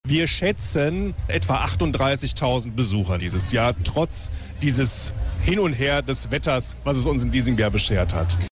Es gab zum Teil sehr heftigen Regen - mit Folgen, so Landrat Andreas Müller am Radio Siegen-Mikrofon. Es seien rund 20.000 Besucherinnen und Besucher weniger gekommen als noch letztes Jahr.
Andreas Müller (Landrat Kreis Siegen-Wittgenstein)Rund 38.000 Besucher